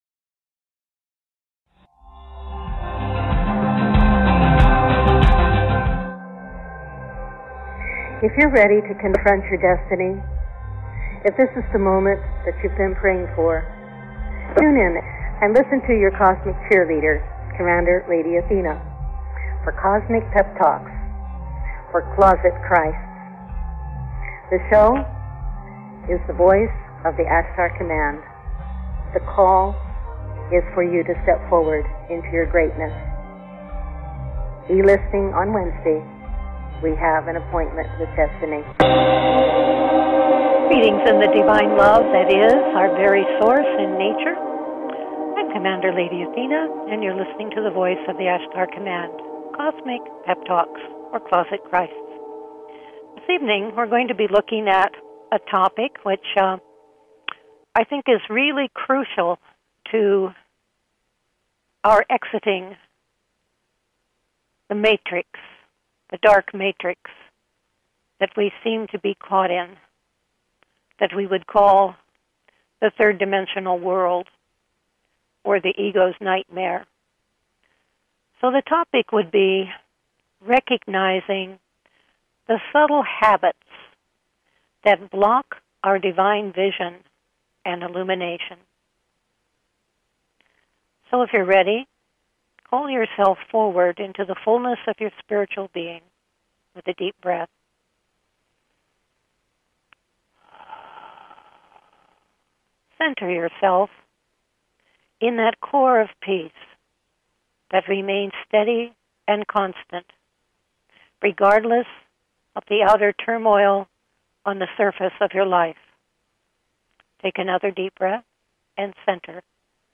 Subscribe Talk Show